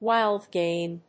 wild+game.mp3